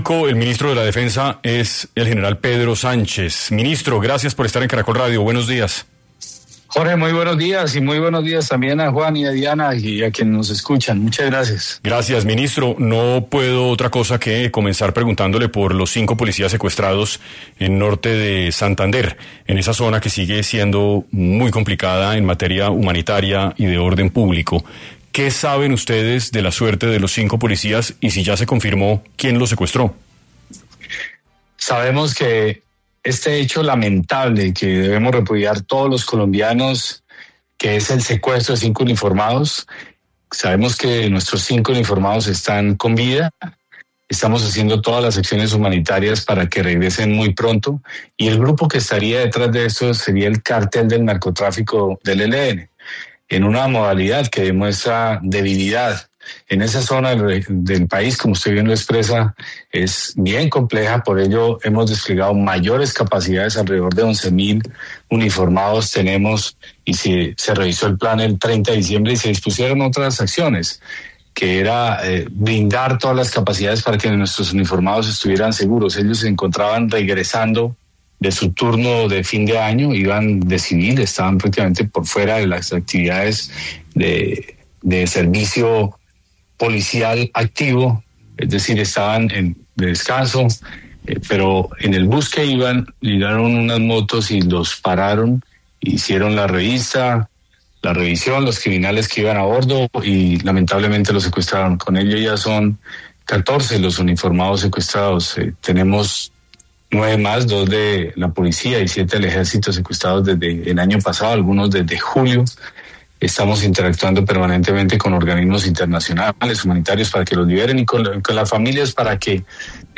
En 6AM de Caracol Radio estuvo el Ministro de Defensa, Pedro Sánchez, quien dio detalles de los 5 policías secuestrados en en la vía que comunica a Cúcuta con Tibú en Norte de Santander